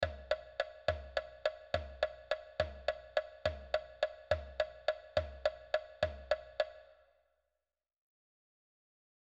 • No comenzo hai unha frase que emprega un ritmo de tresillos, baixando encaracoladamente coma lembrando a entrada dunha gaita.
Escoita tresillos coma os que soan nesa frase que abre a canción:
ritmo_tresillos.mp3